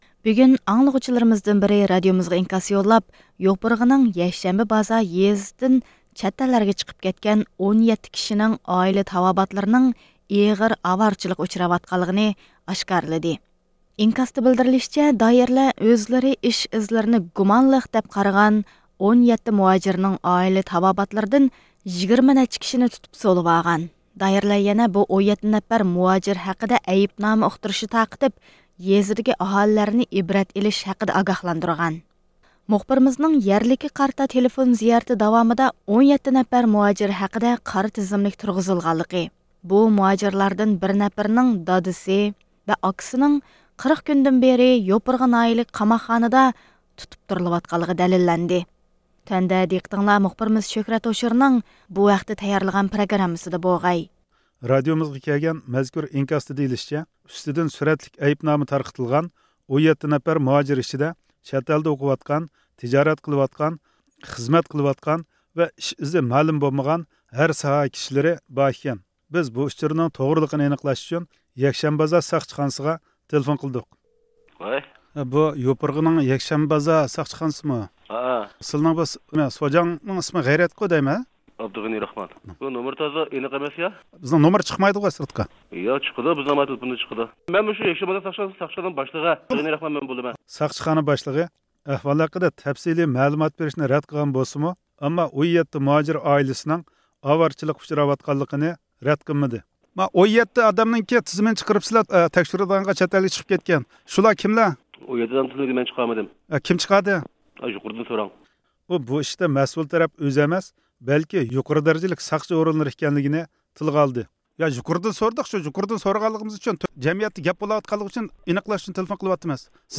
مۇخبىرنىڭ يەرلىككە قارىتا تېلېفون زىيارىتى داۋامىدا، 17 نەپەر مۇھاجىر ھەققىدە قارا تىزىملىك تۇرغۇزۇلغانلىقى، بۇ مۇھاجىرلاردىن بىر نەپىرىنىڭ دادىسى ۋە ئاكىسىنىڭ 40 كۈندىن بېرى يوپۇرغا ناھىيىلىك قاماقخانىدا تۇتۇپ تۇرۇلۇۋاتقانلىقى دەلىللەنگەن.